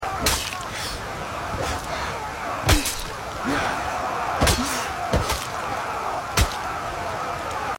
The perspective of a medieval warrior storming through a battlefield, shield raised against flying arrows, mud splattering the camera lens, with the sound of swords clashing and the heavy breathing of the warrior.